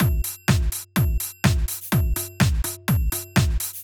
Drumloop 125bpm 09-A.wav